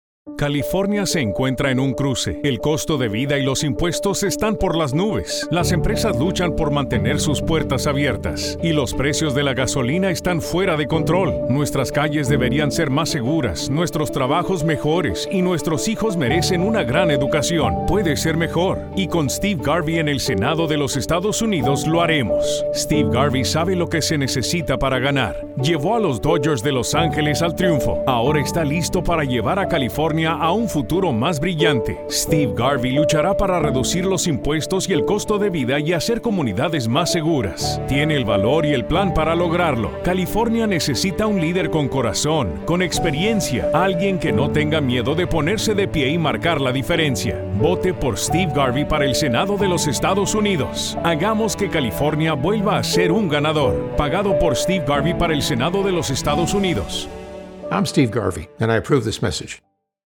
Garvey-Radio-60-Spanish-LA.mp3